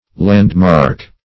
Landmark \Land"mark`\, n. [AS. landmearc. See Land, and Mark